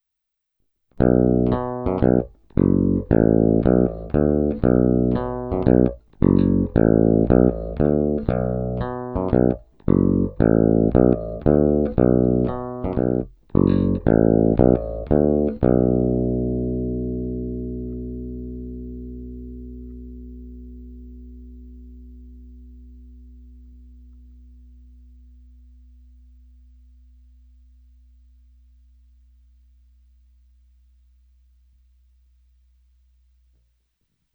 Obecně zvuk této baskytary má ostřejší zvuk s kovovým nádechem, proto jsem i ve všech navazujících ukázkách stáhnul cca o 1/3 tónové clony obou snímačů.
Není-li uvedeno jinak, následující nahrávky jsou provedeny rovnou do zvukové karty, jen normalizovány, jinak ponechány bez úprav.
Snímač u kobylky